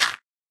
gravel1.ogg